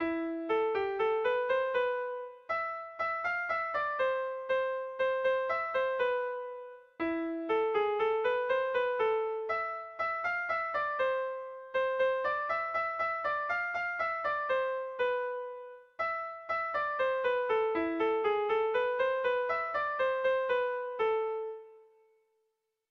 Sentimenduzkoa
abde...